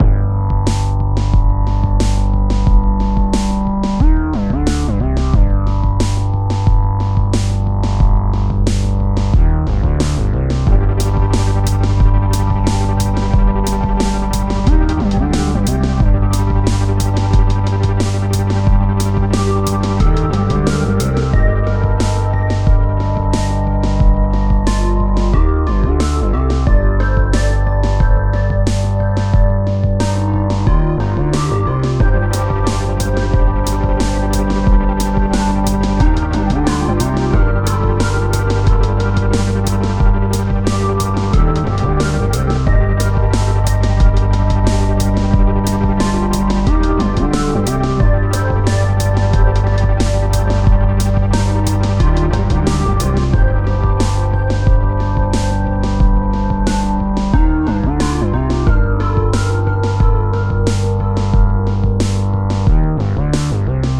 A remix